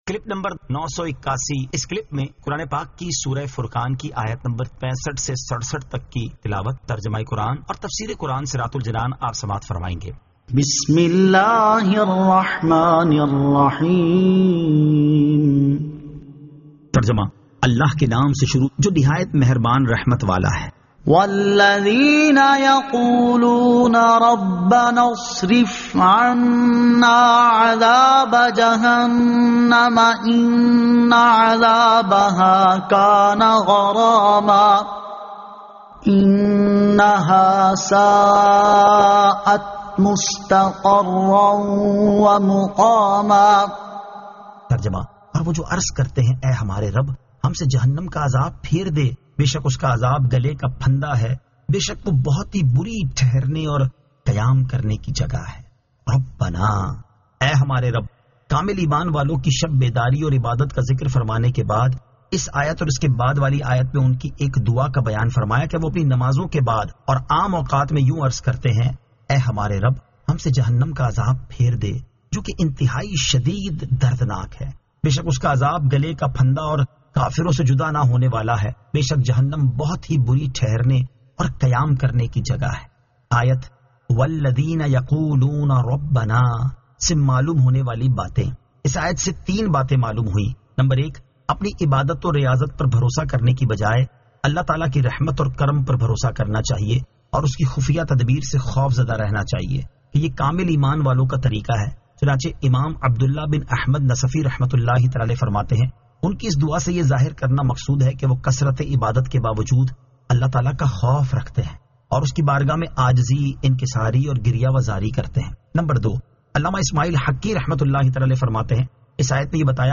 Surah Al-Furqan 65 To 67 Tilawat , Tarjama , Tafseer